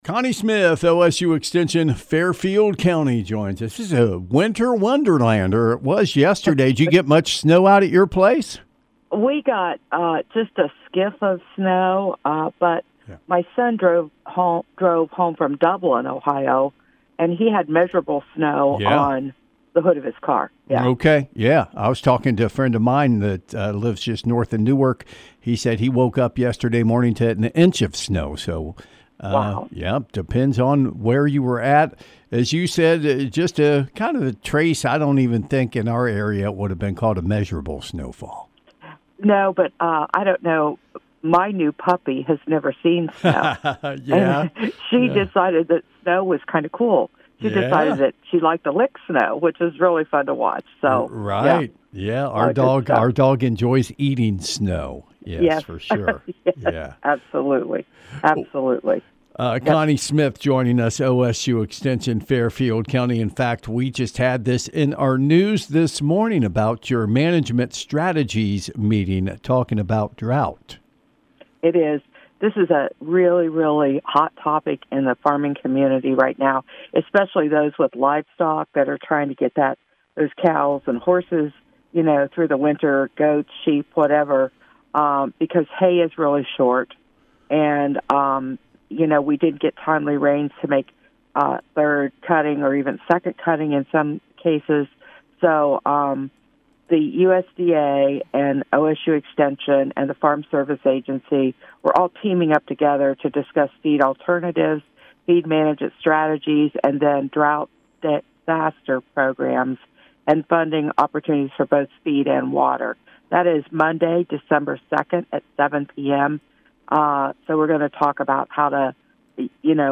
Listen for the ‘Saturday Morning Farm Page’ each Saturday from 6 am – 8 am with agriculture news, analysis, and interviews!